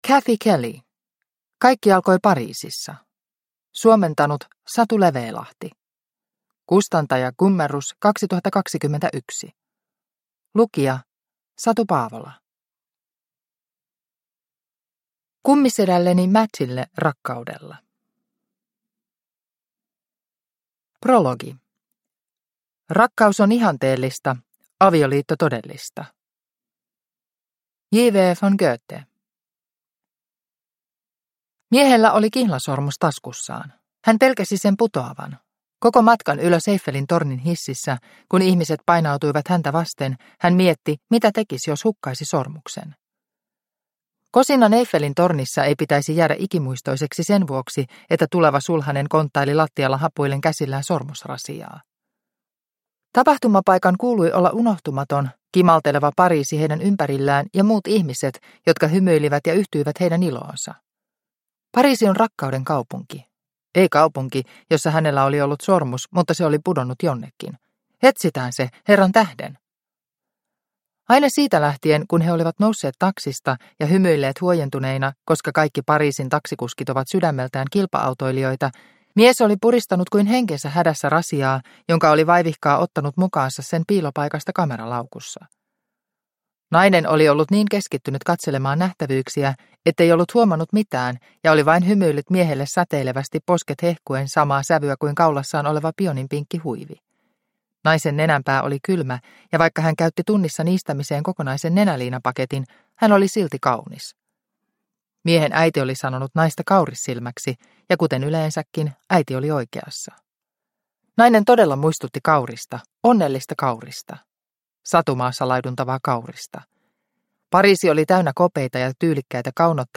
Kaikki alkoi Pariisissa – Ljudbok – Laddas ner